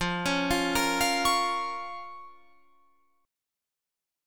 Fsus4#5 Chord (page 3)
Listen to Fsus4#5 strummed